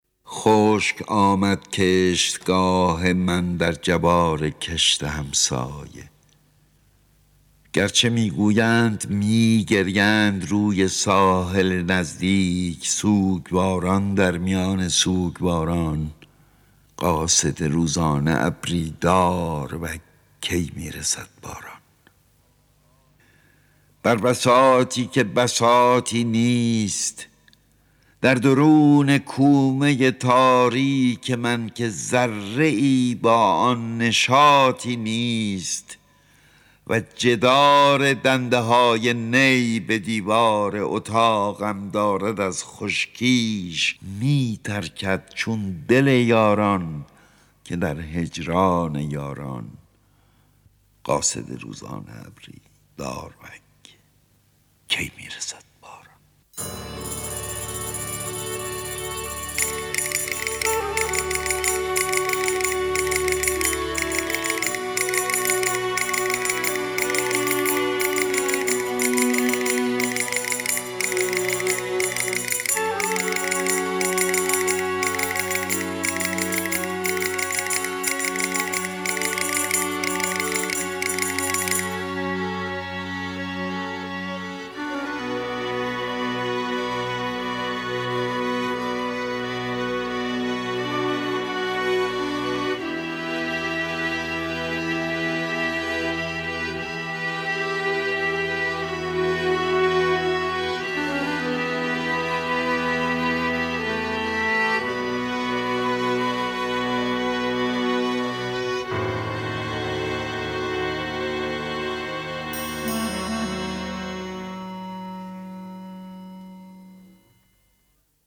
دانلود دکلمه داروک با صدای احمد شاملو
گوینده :   [احمد شاملو]